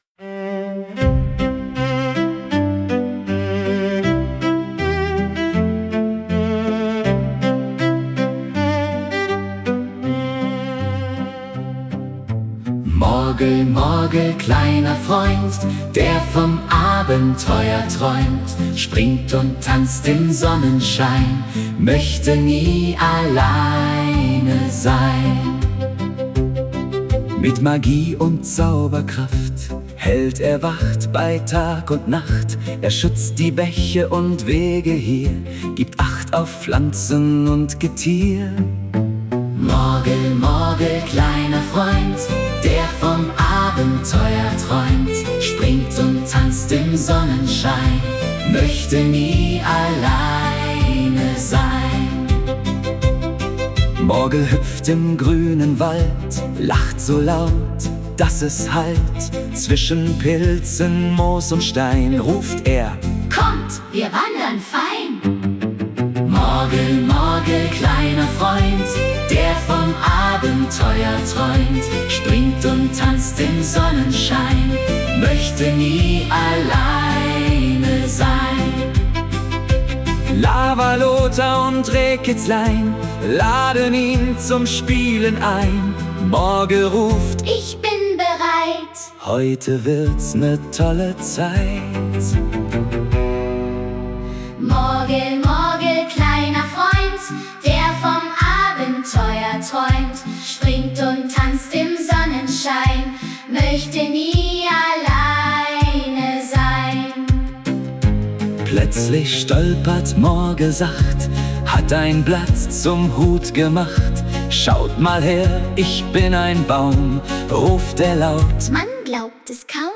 AI‑generated voices